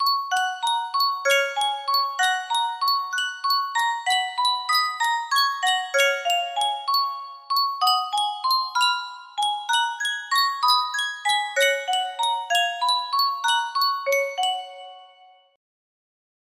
Sankyo Boite a Musique - Plaisir d'amour CUM music box melody
Full range 60